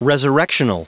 Prononciation du mot resurrectional en anglais (fichier audio)
Prononciation du mot : resurrectional